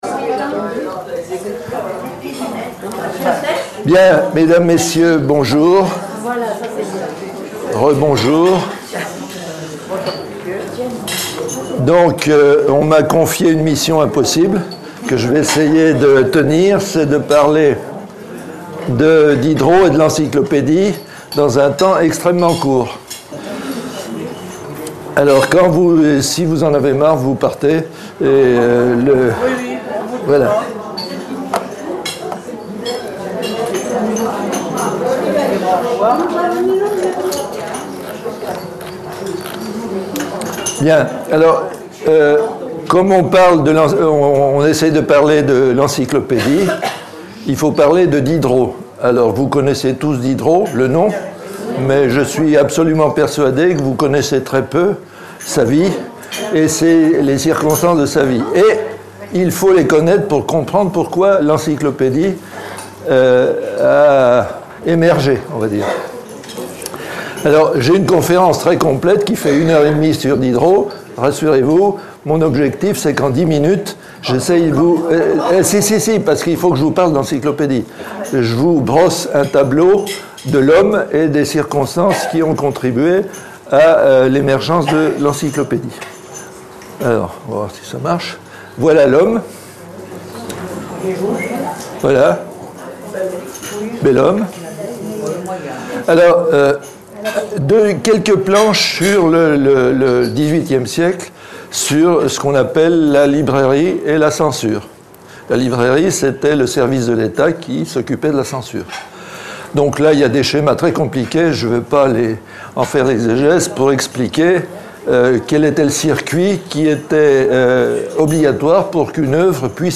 Enregistrement conférence Encyclopédie